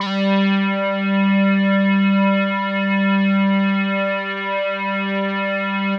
G3_trance_lead_2.wav